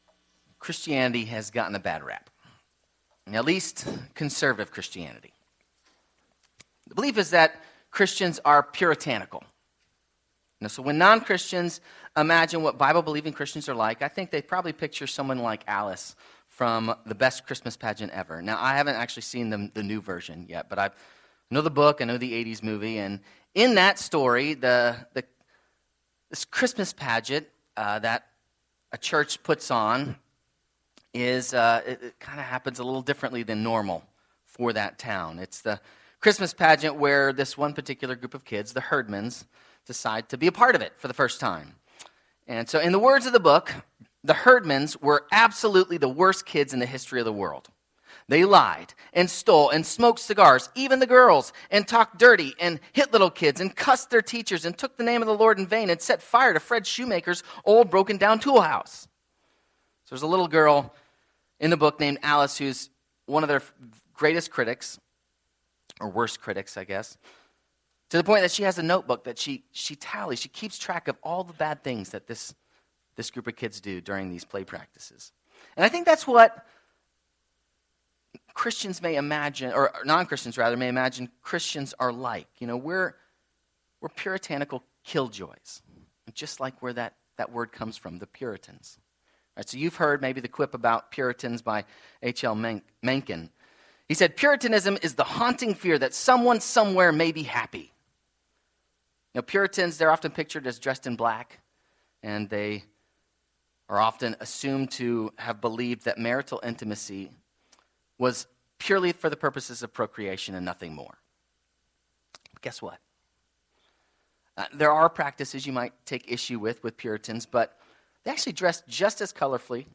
Sermons – FBCNC